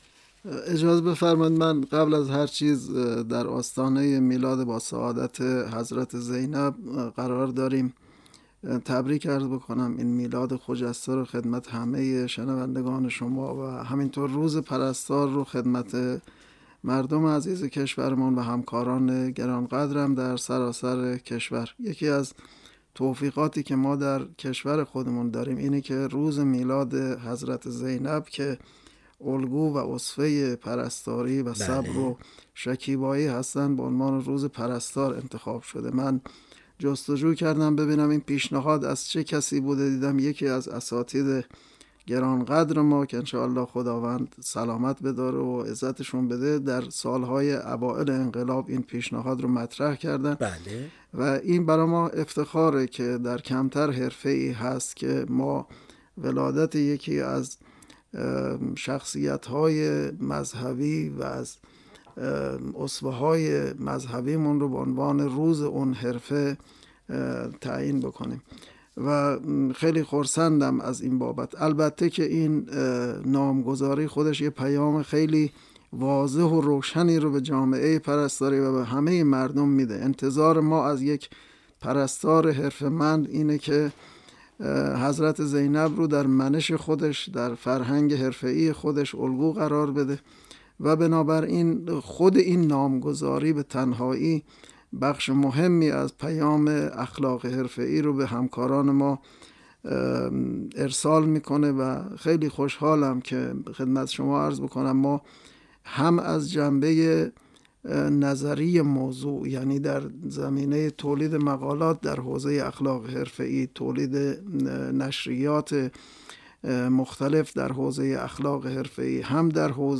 حاصل این گپ‌و‌گفت در ادامه از خاطر شما می‌گذرد.